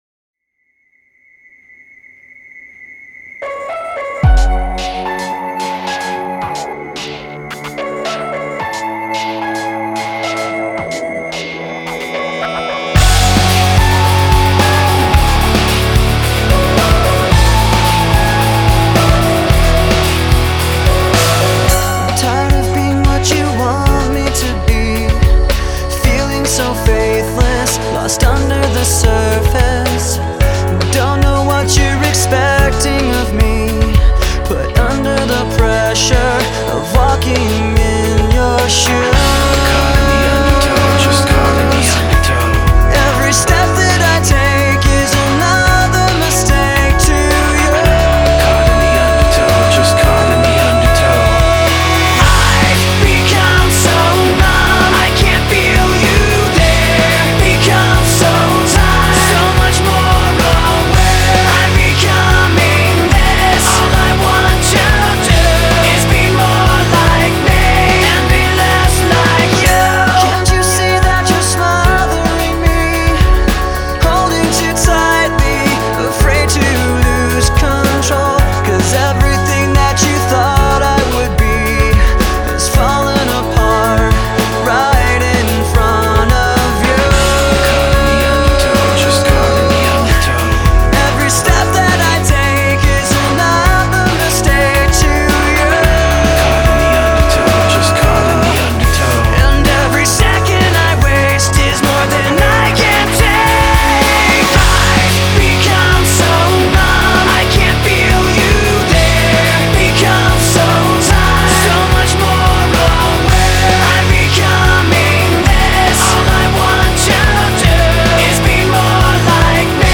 Зарубежный Рок